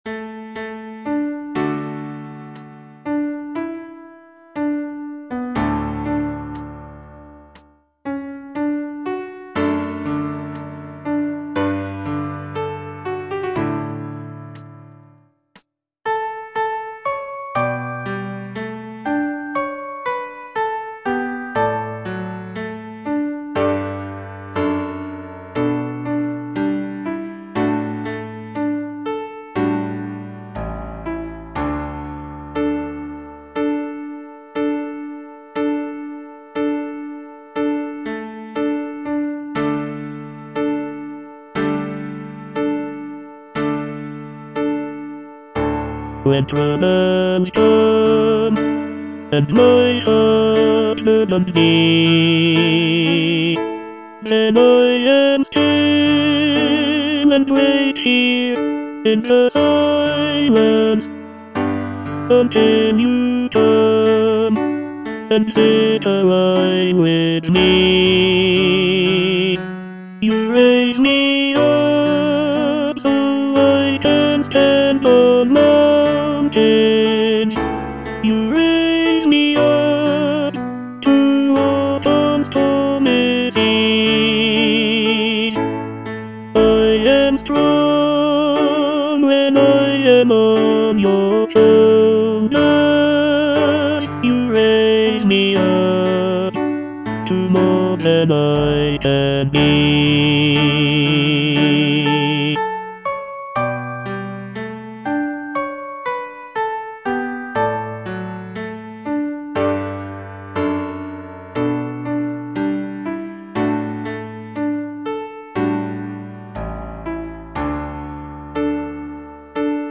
Tenor (Voice with metronome)